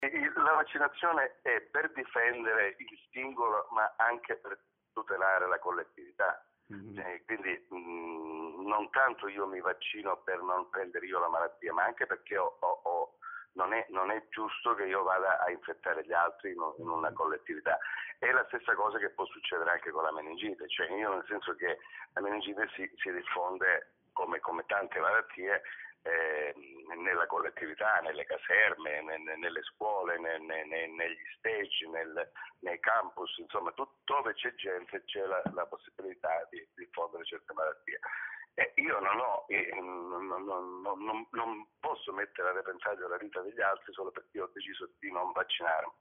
La prima parte dell’intervista